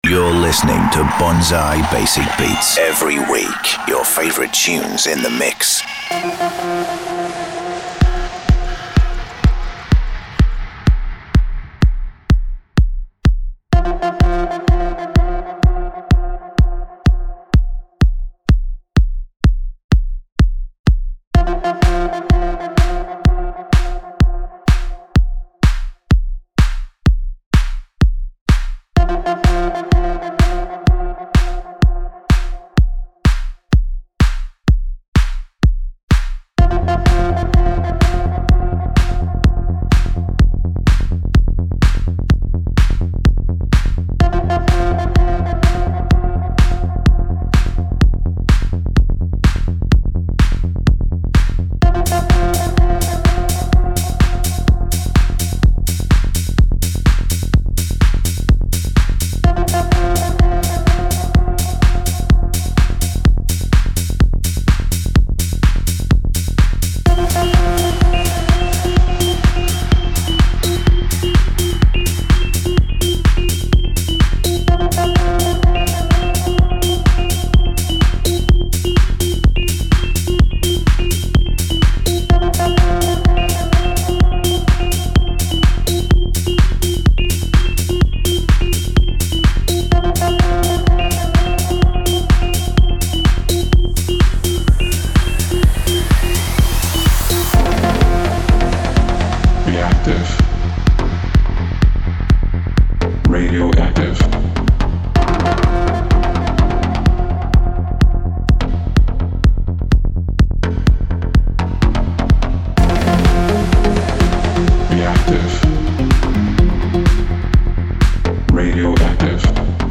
Filled with lush progressive vibes